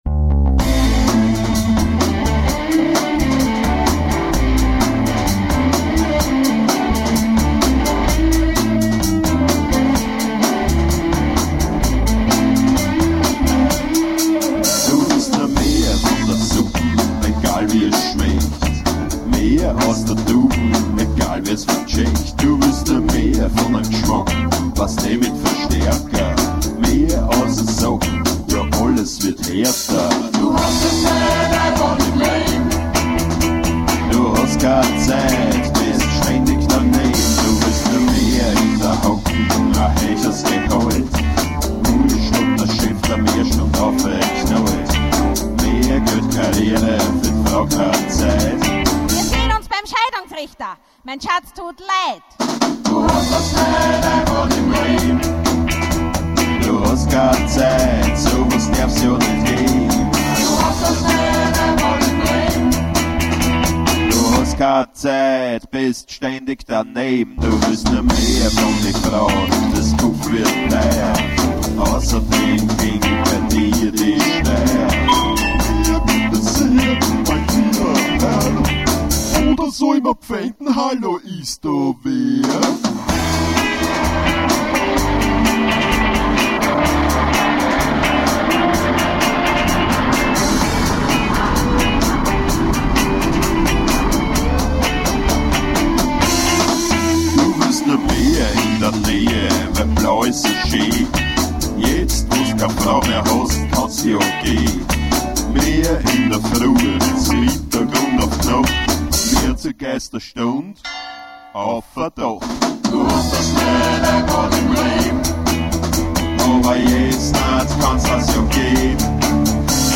Genre: Freie Musik - Rock